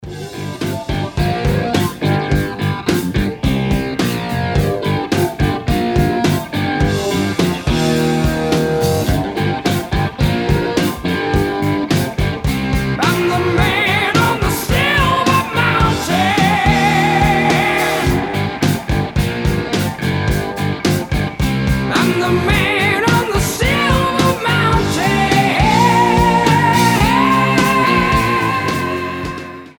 рок
гитара , heavy metal